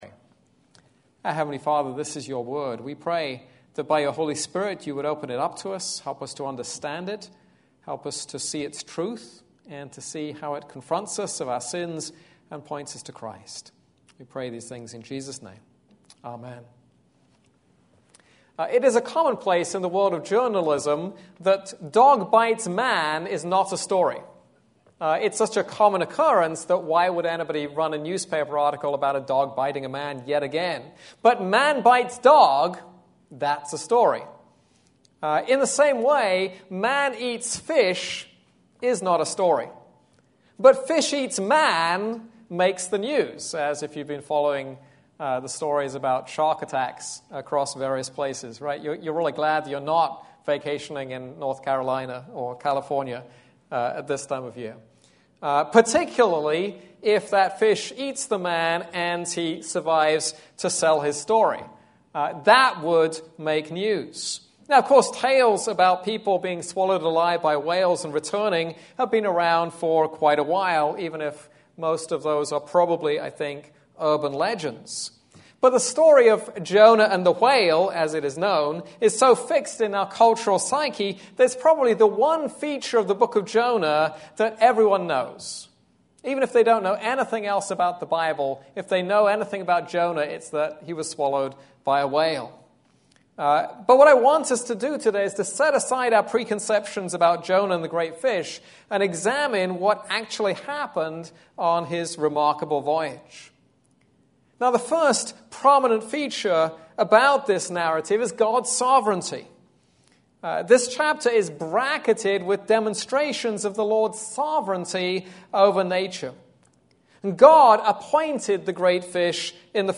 This is a sermon on Jonah 1:17-2:10.